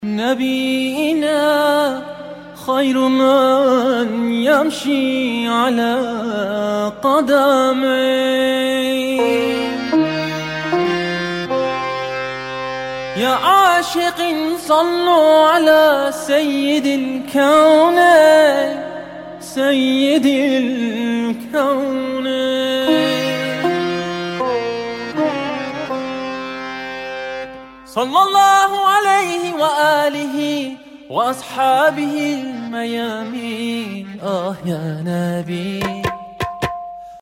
رینگتون(با کلام) ملایم با ملودی پاکستانی